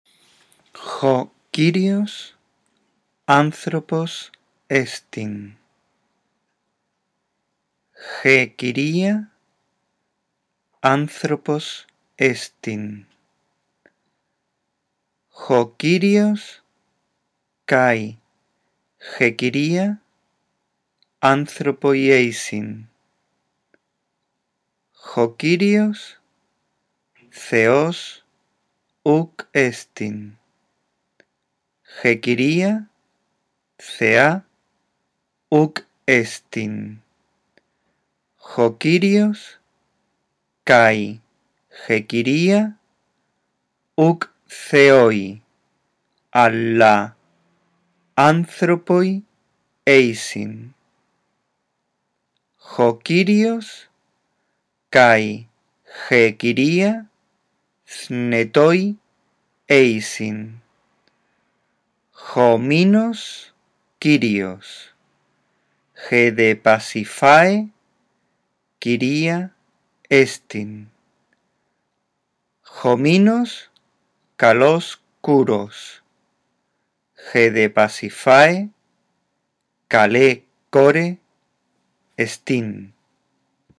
Lee despacio y en voz alta este primer texto griego que nos habla del Minotauro; detente en cada signo de puntuación. Después de haberlo leído una vez, escucha este archivo de audio y atiende a cómo se unen las palabras; la unión de las palabras te ayudará a comprender el texto.